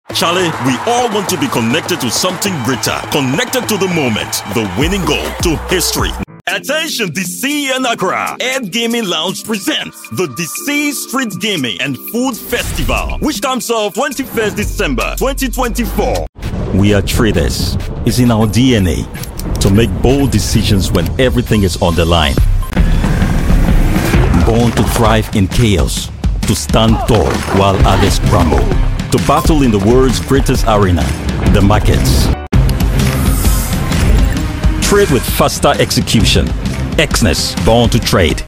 Anglais (Africain)
De la conversation
Optimiste
Chaleureux